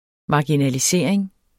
Udtale [ mɑginaliˈseˀɐ̯eŋ ]